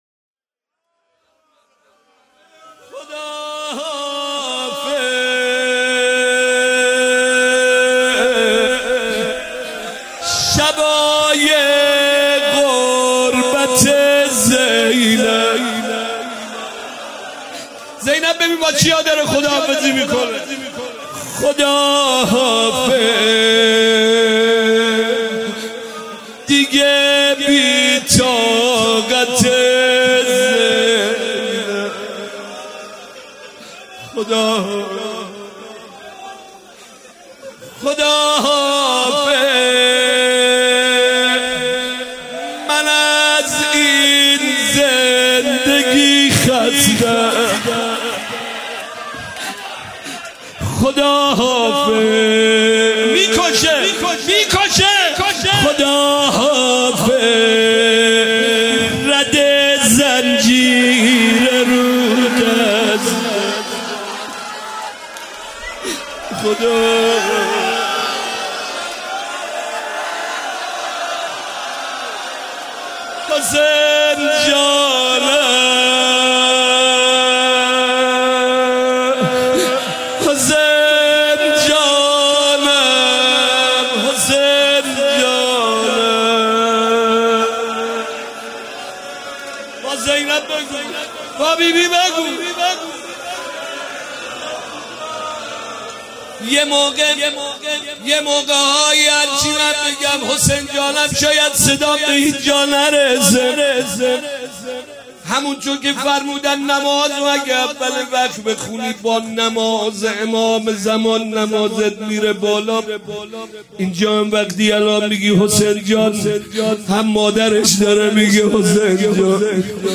شهادت حضرت زینب (س) 97 - روضه - خداحافظ شبای غربت زینب